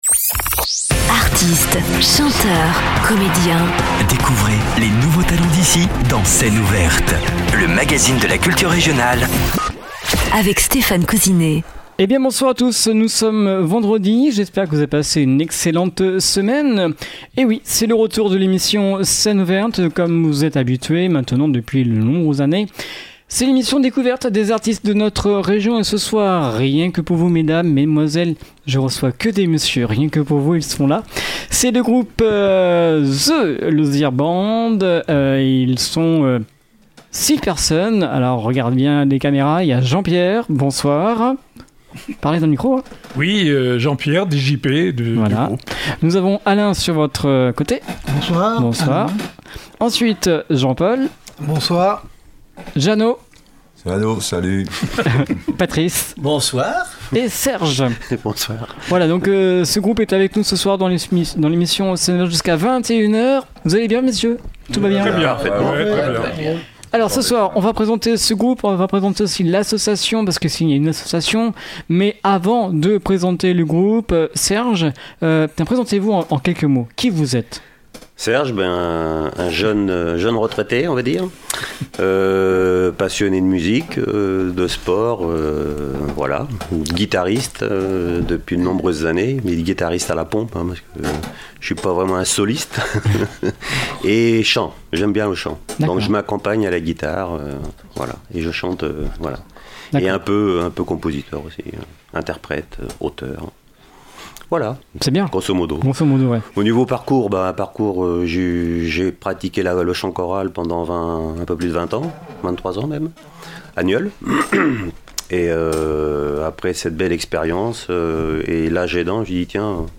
Scène ouverte du 31/03/23 avec Zhe Lauzières Band.
Son répertoire est le rock et variétés française, et quelques compositions.